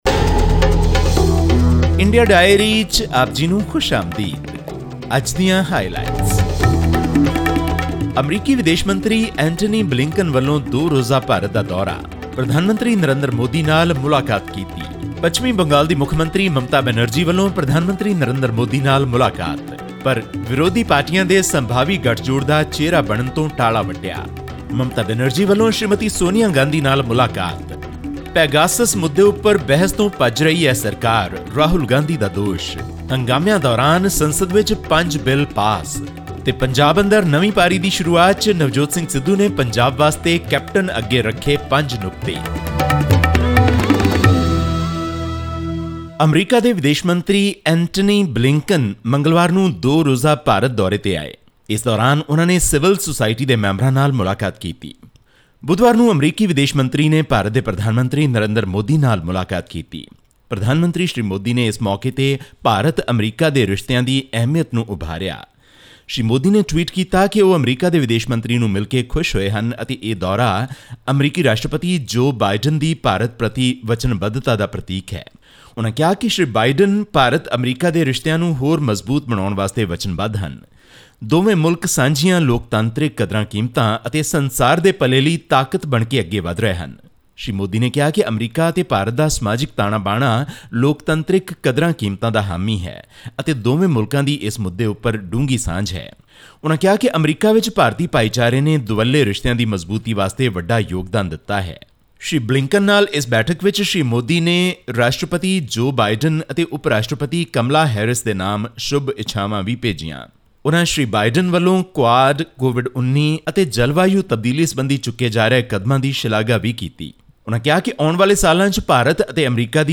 Newly appointed Punjab Congress president Navjot Singh Sidhu and the four working presidents met chief minister Capt Amarinder Singh on Tuesday demanding immediate action on five key issues including sacrilege and police firing cases, arrest of the drug mafias and rejection of three farm laws. All this and more in our weekly news segment from India.
Click on the player at the top of the page to listen to the news bulletin in Punjabi.